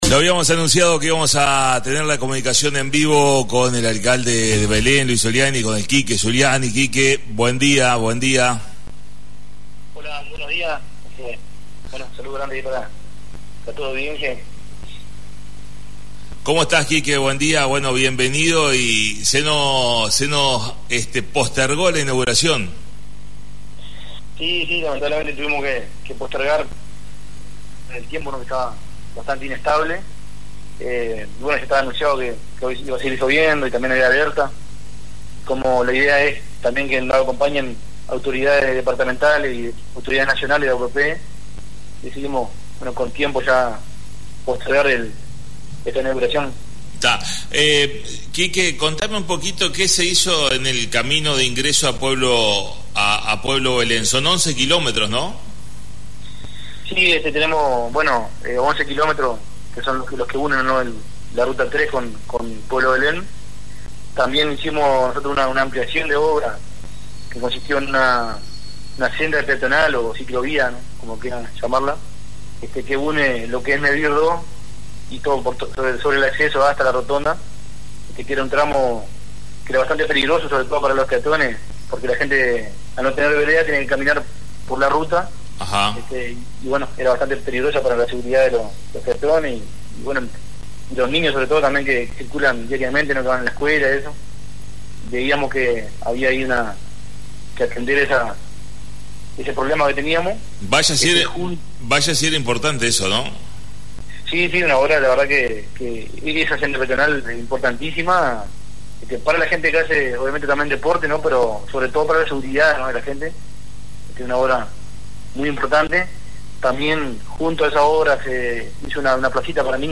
Comunicación en vivo con Quique Zuliani Alcalde de Belén